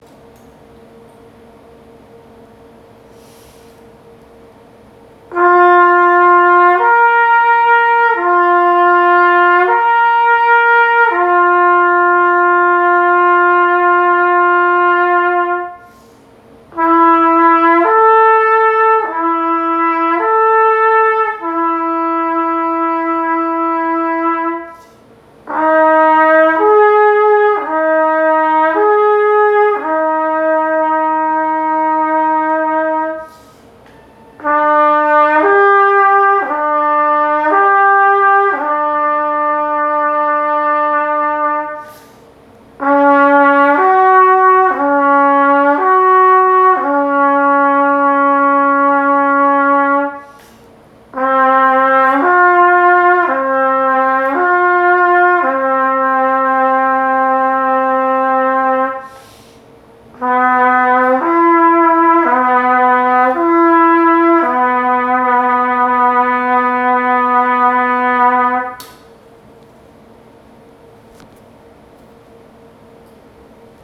滑らかに音が変わるとこんな感じです。
【音の変わり目がスムーズ】
リップスラー.wav